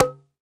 Sfx Nerf Gun Shoot Sound Effect
sfx-nerf-gun-shoot-1.mp3